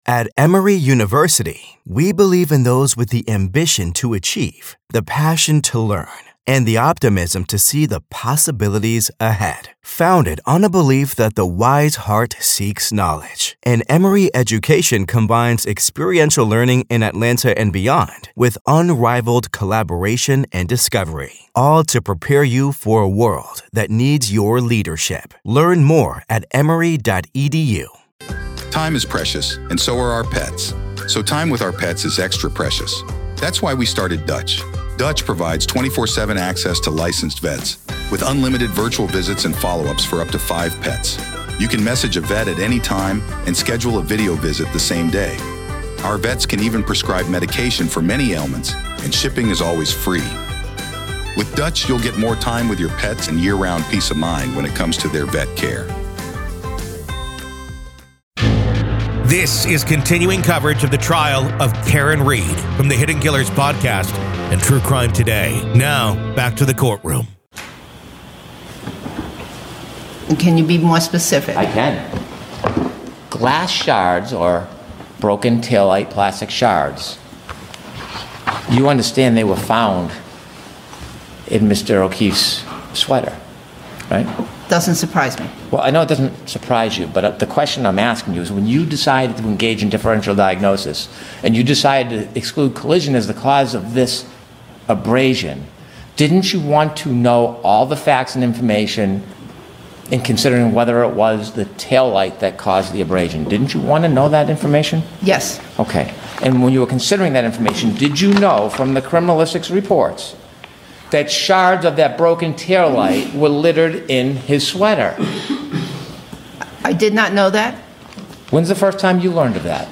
MA v. Karen Read Murder Retrial - Dog Bite Expert Daubert Hearing PART 2